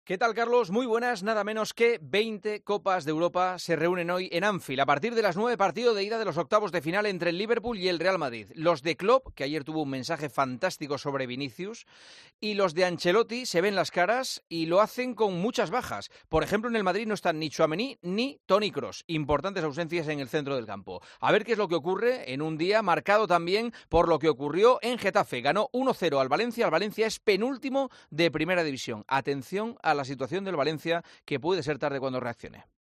Juanma Castaño le explica a Carlos Herrera el calibre del Liverpool - Real Madrid: "20 Copas de Europa"
El comentario de Juanma Castaño
El presentador de 'El Partidazo de COPE' analiza la actualidad deportiva en 'Herrera en COPE'